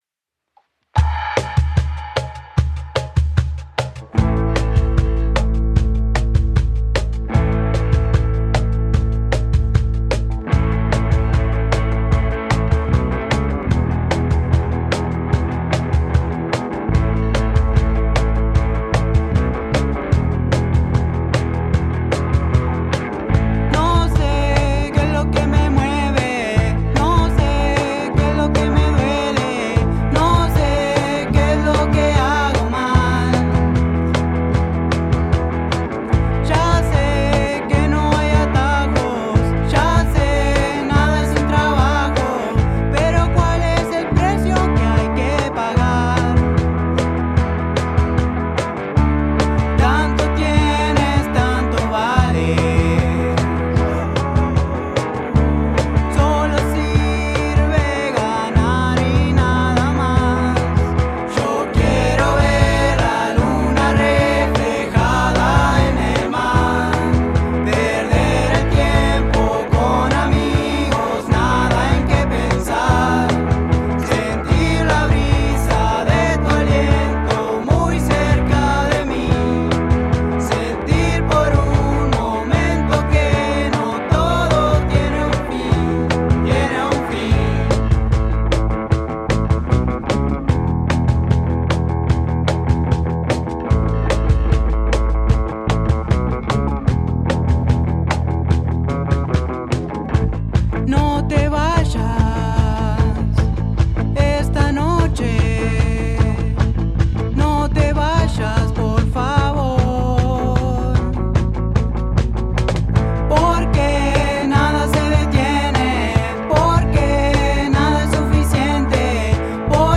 Desde la Gerencia de Formación Cultural, con el objetivo de potenciar las instancias formativas y en coordinación con la Usina Cultural de Parque del Plata, se grabaron productos musicales de los participantes de los talleres que se llevan a cabo en el departamento.